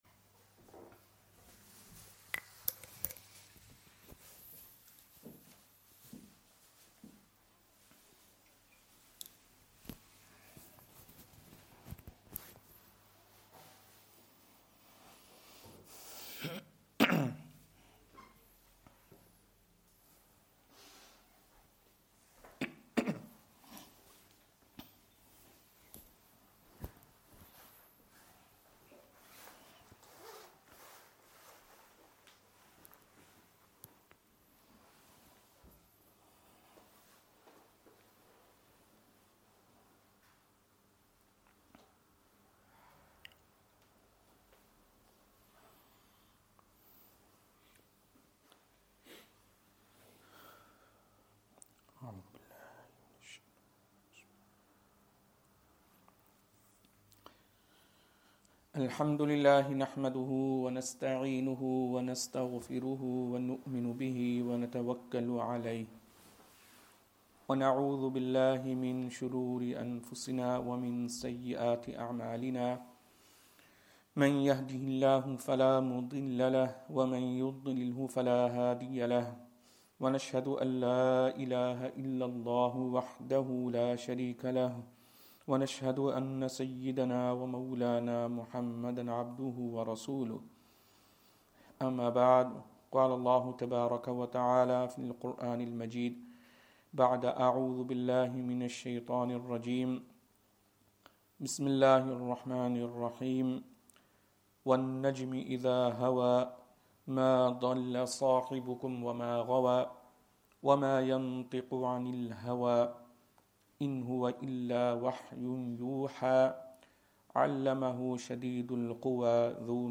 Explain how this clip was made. Madni Masjid, Langside Road, Glasgow